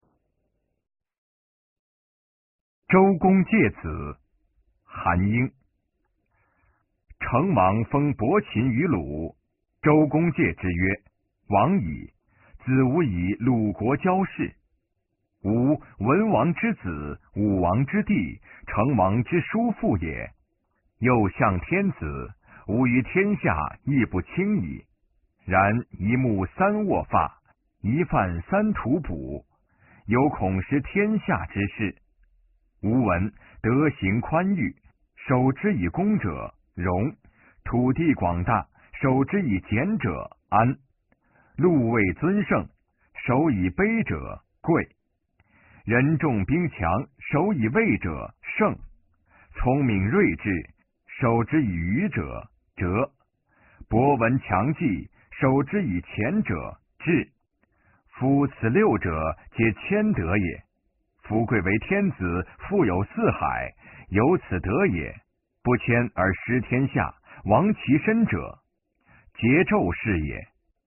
《周公诫子》原文与译文（含在线朗读）　/ 佚名